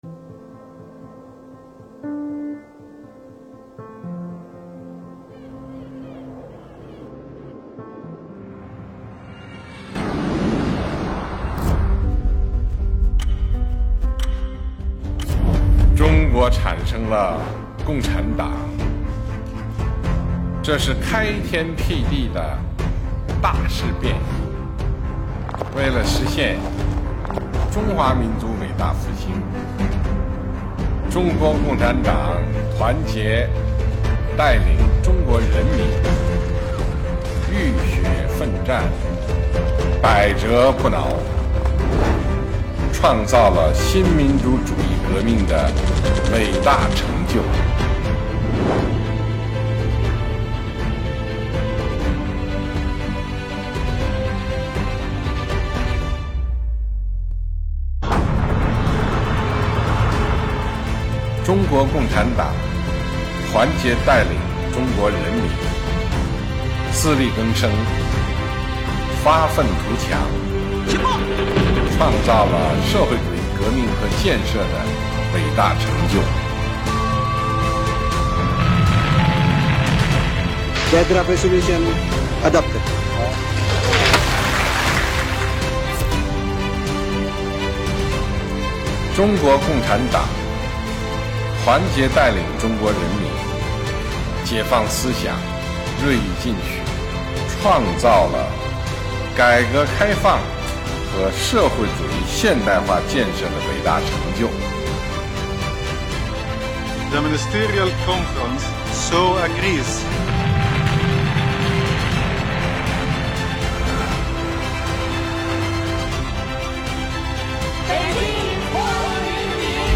跟随习近平总书记的原声